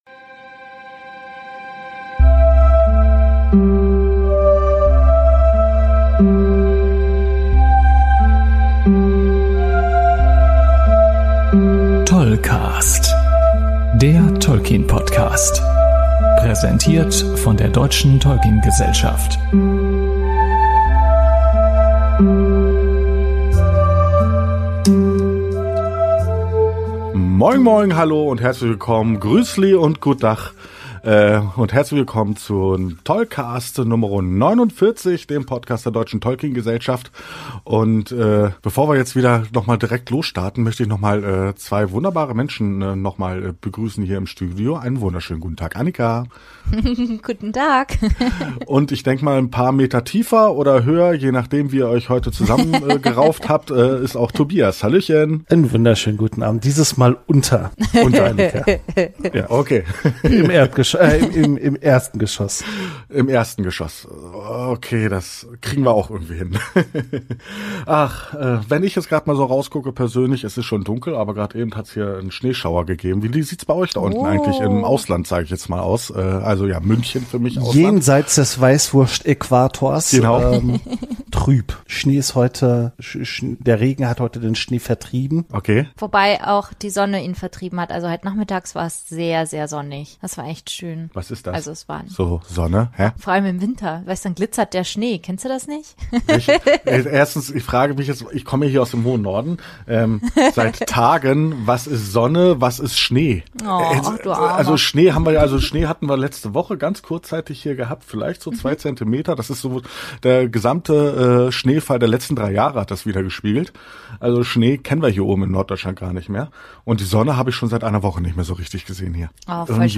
Aber vehement ertönt nach einer Dreiviertelstunde das Horn, das die Moderatoren eigentlich zum Ende bringen soll.